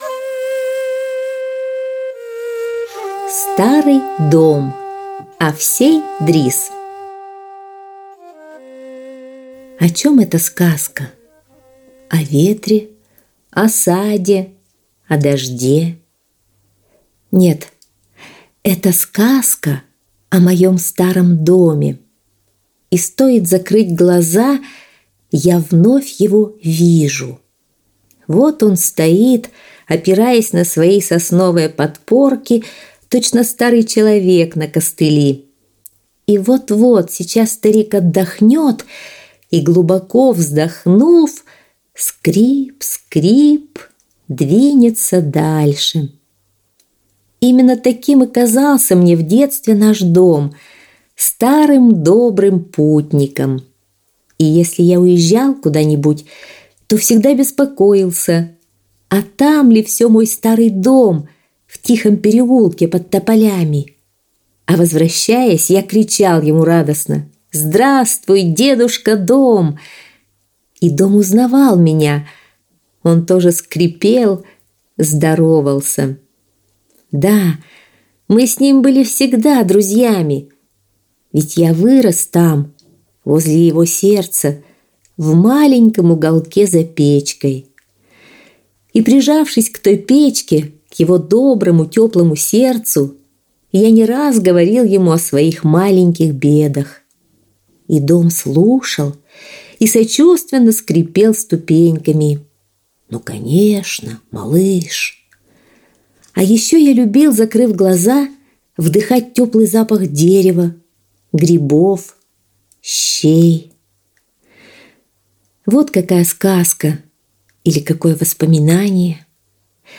Аудиосказка «Старый дом»